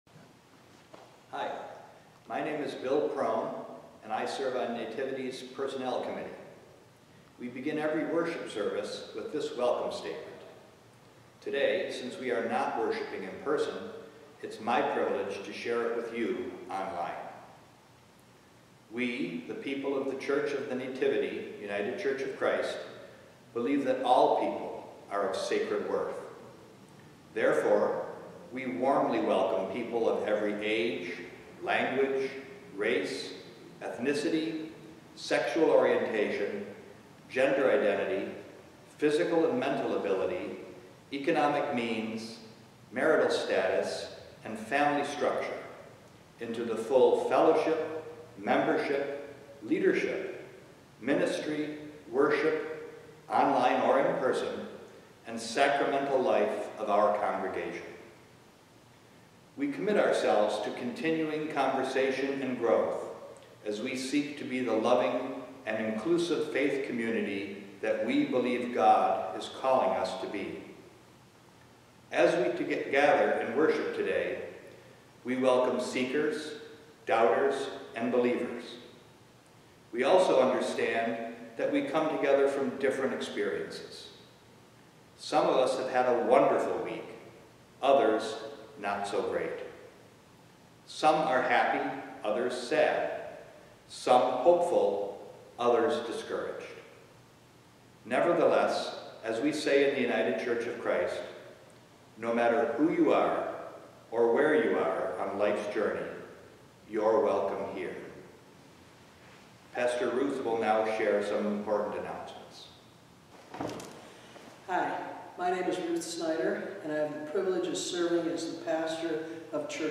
Sermon 3-29-20
Genre Sermon or written equivalent